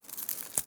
SFX_Harvesting_01.wav